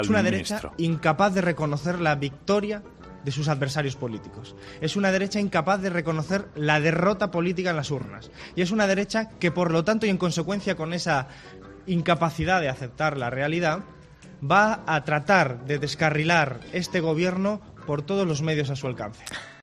Y el autor de ‘La gran estafa’, compareció ante los medios para hacer su análisis de lo que está ocurriendo.
Declaraciones de Alberto Garzón sobre la oposición